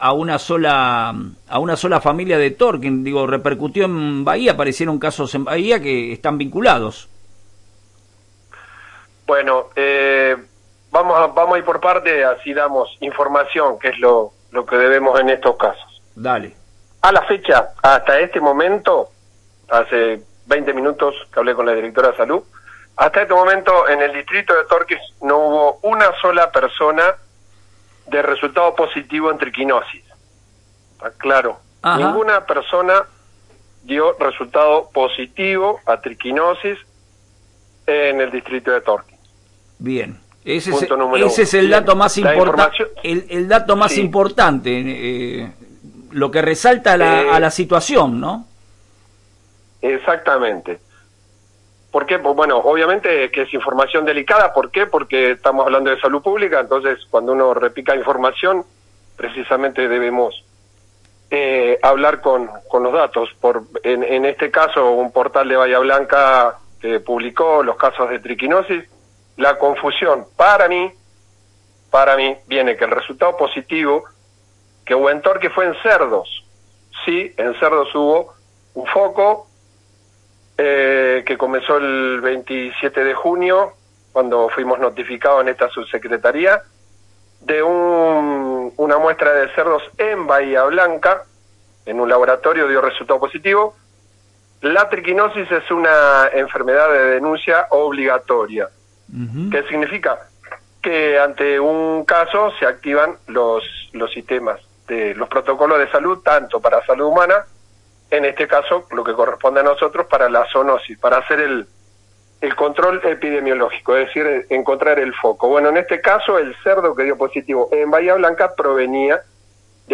El Dr. Juan Manuel Gonzalez Sáenz, subsecretario de Bromatología de Tornquist informa sobre los casos de Triquina en nuestro distrito.